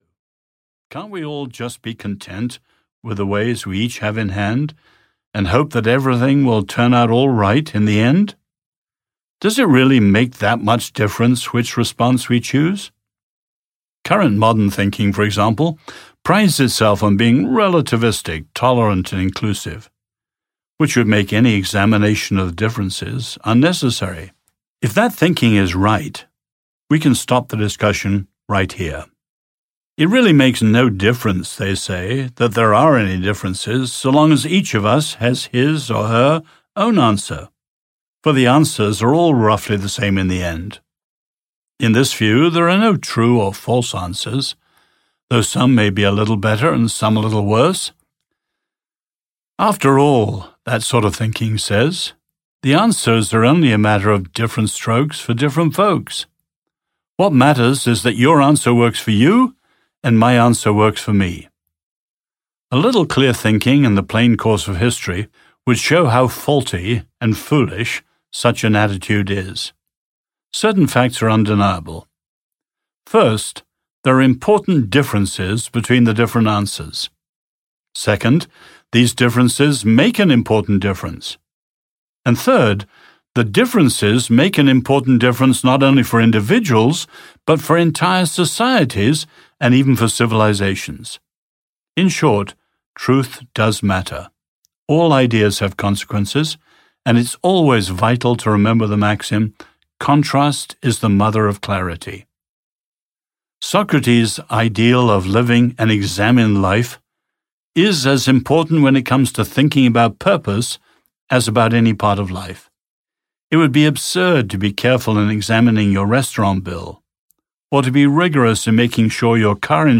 The Call Audiobook
Narrator